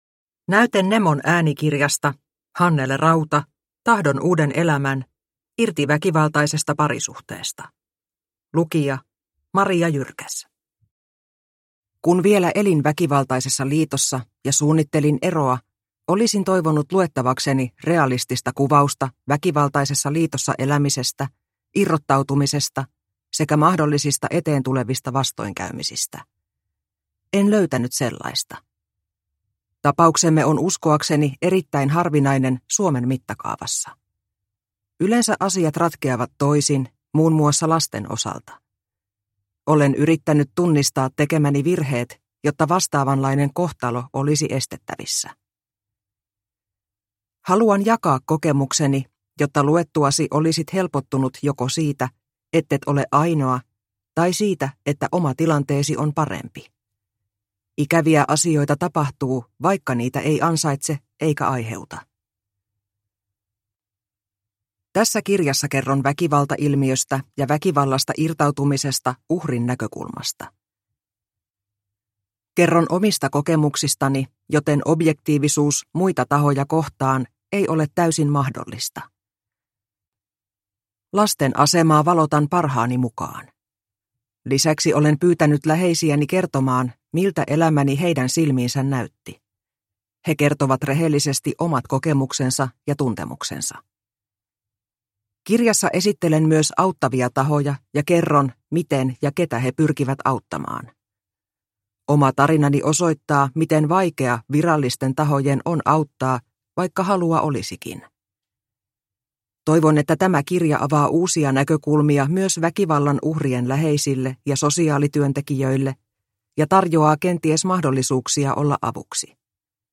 Tahdon uuden elämän – Ljudbok – Laddas ner